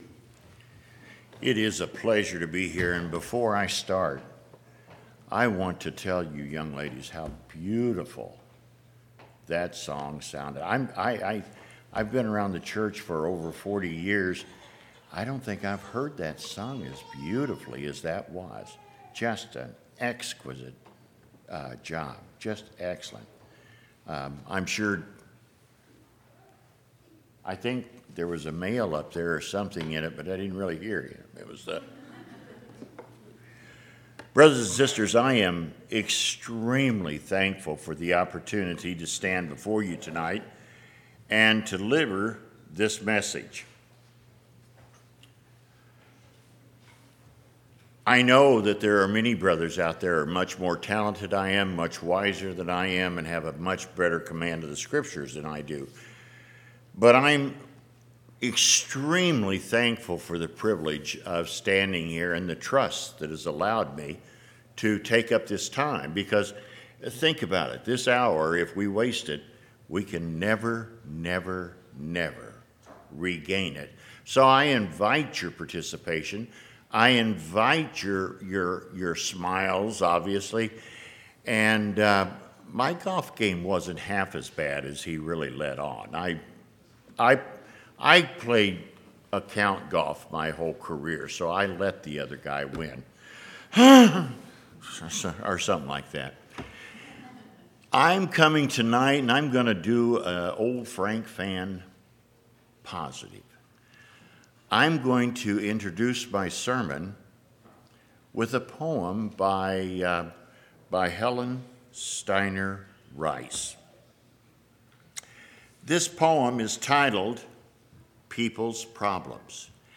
The words of Helen Steiner Rice begin the sermon with poetic grace followed by excerpts from King Benjamin’s proclamation as well as verses from Mosiah. The basic tenets of Faith, Hope, Saved by Grace, and Judged according to our works.
7/27/2019 Location: Missouri Reunion Event